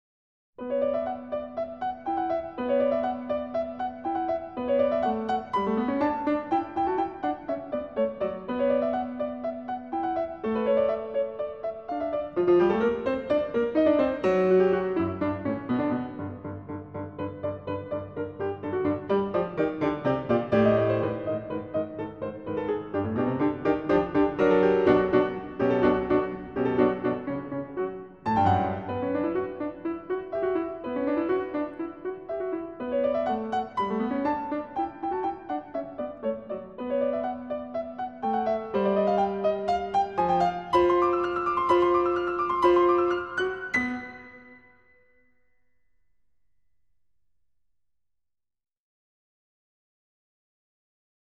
0247-钢琴名曲时间木偶.mp3